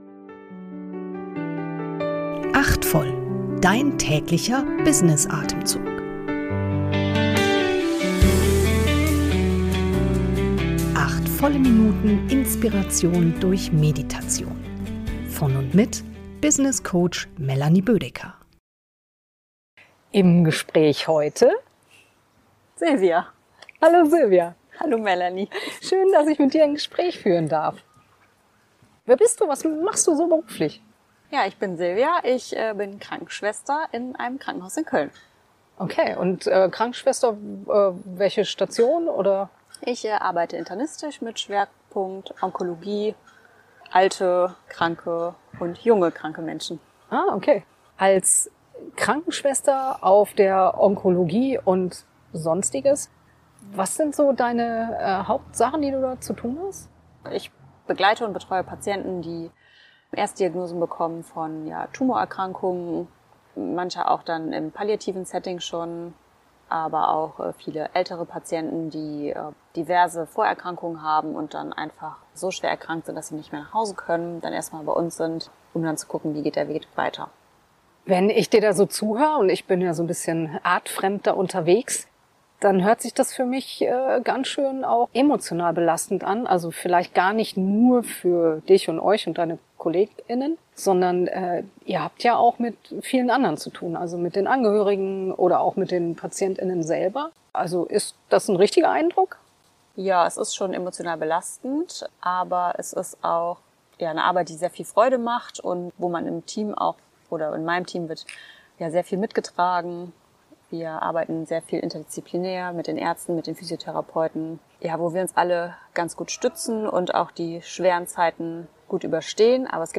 Im Gespräch
Erfrischung durch einen W.A.L.I. als geleitete Kurz-Meditation.